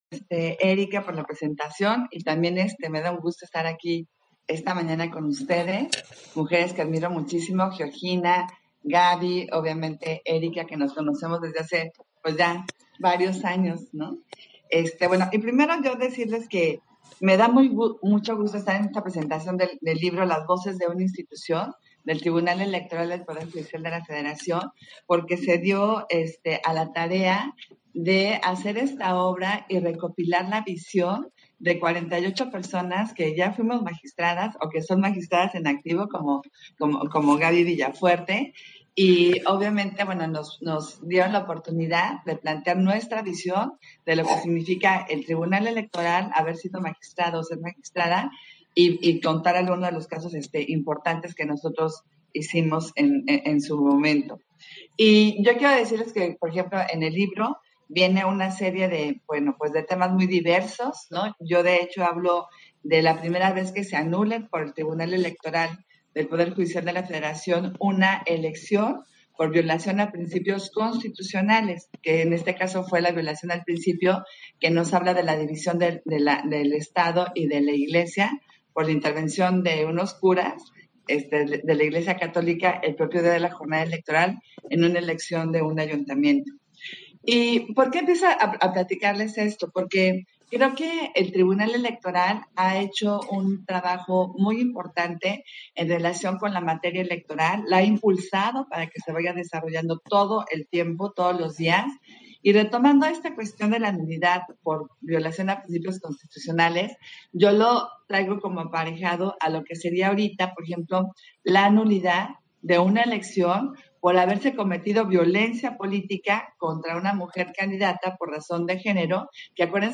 Intervención de Adriana Favela, en la presentación editorial, Las voces de una institución, en la 1era. Feria del libro, Igualdad de género y democracias